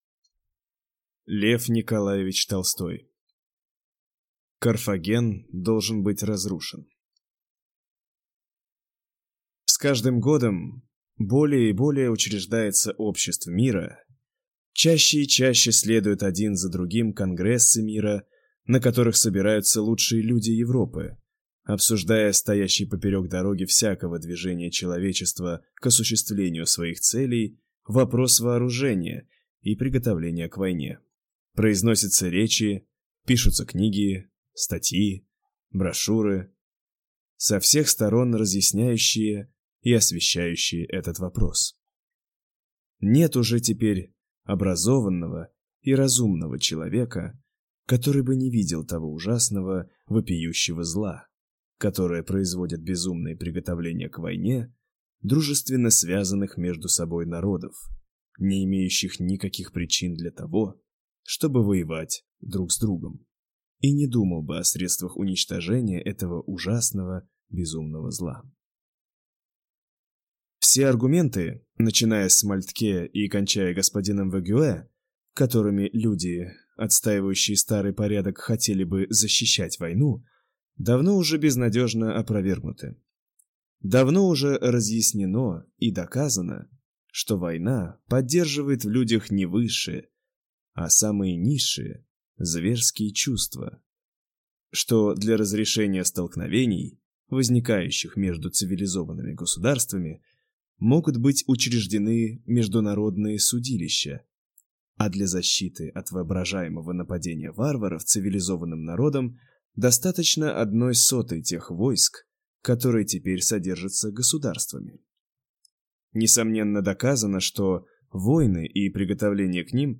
Аудиокнига Carthago delenda est (Карфаген должен быть разрушен) | Библиотека аудиокниг